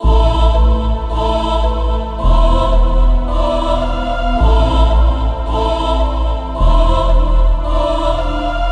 标签： 影视 军队 震感 中国风 企业 帝国 战争 游戏 有声读物 广告
声道立体声